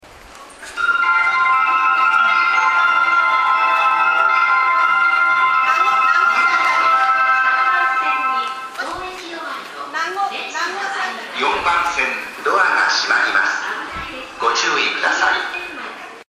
発車メロディーフルコーラスです。